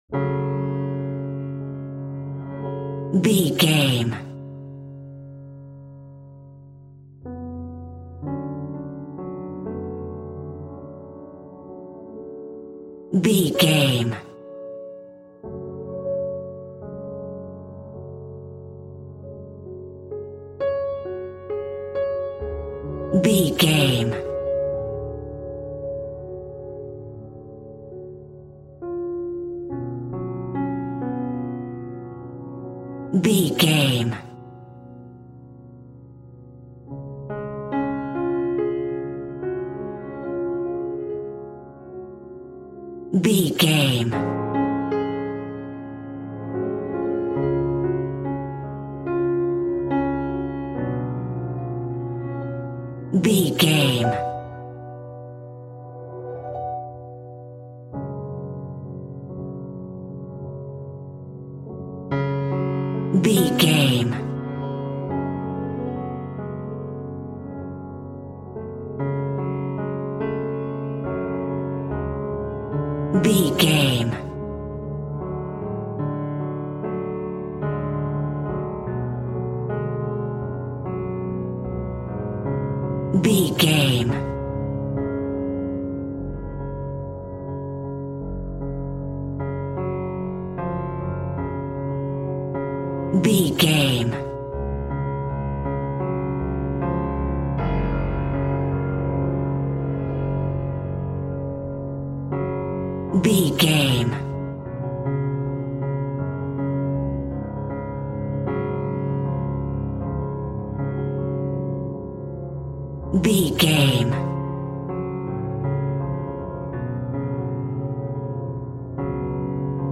Aeolian/Minor
F#
ominous
haunting
eerie
Scary Piano